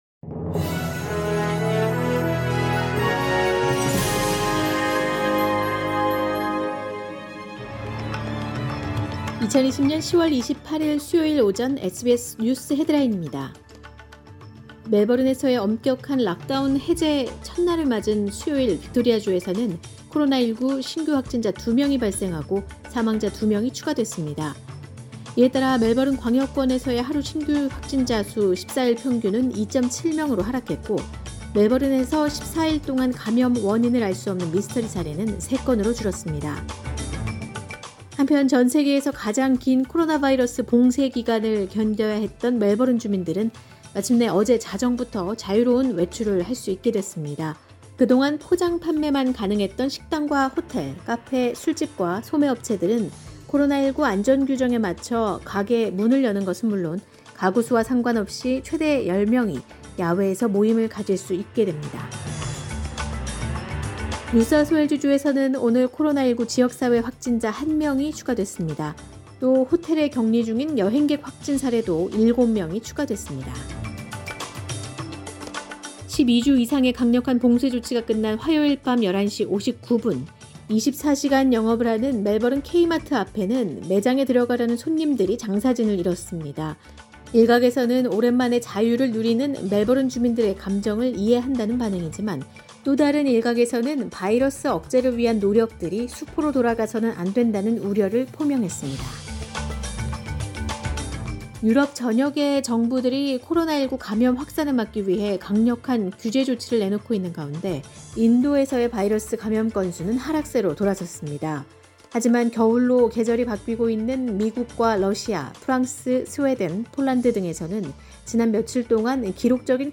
SBS News Headlines…2020년 10월 28일 오전 주요 뉴스
2020년 10월 28일 수요일 오전의 SBS 뉴스 헤드라인입니다.